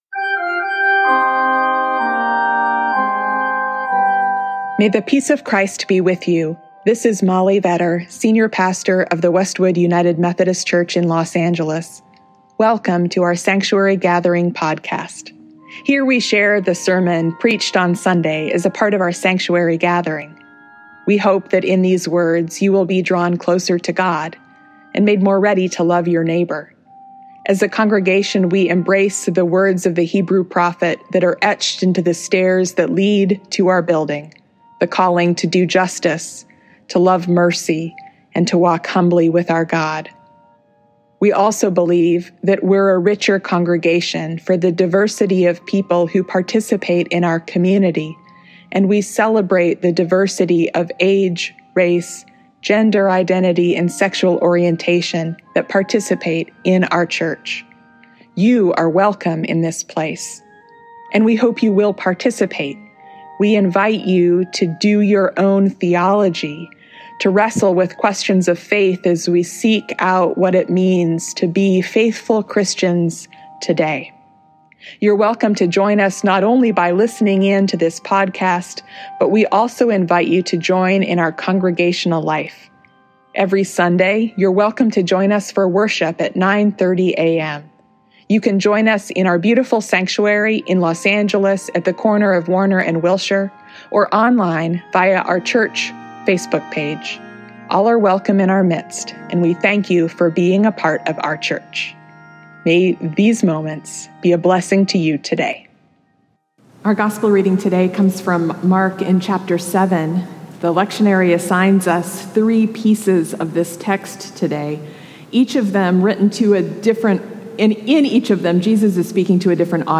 This Sunday, we begin a sermon series that moves through the Epistle of James.